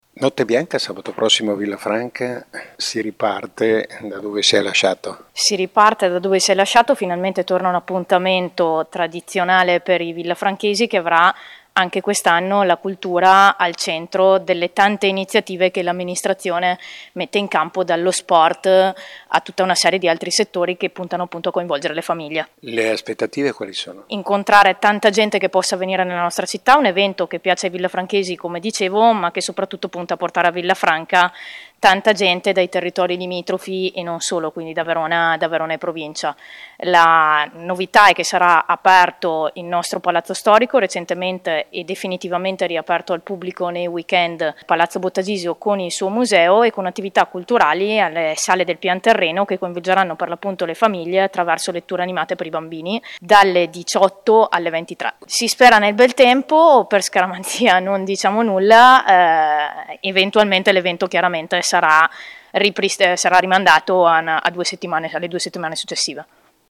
Le interviste del nostro corrispondente
Claudia Barbera, assessore alla cultura e alle pari opportunità